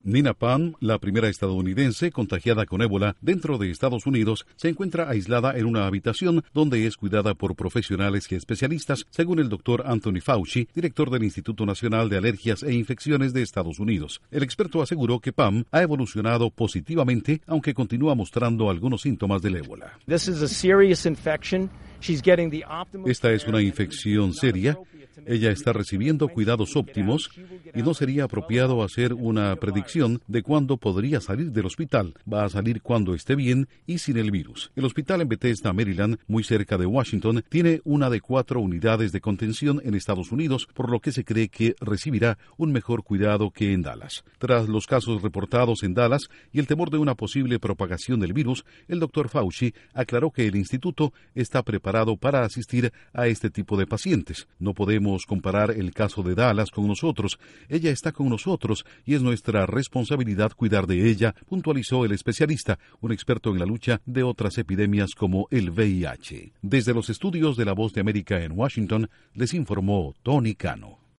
Informa desde los estudios de la Voz de América